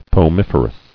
[po·mif·er·ous]